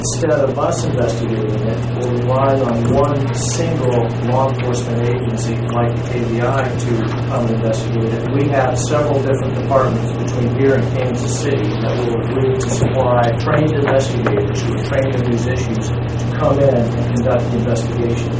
RCPD Director Dennis Butler says this M.O.U. is an agreement between multiple law enforcement departments to assist each other when an officer is involved in a shooting.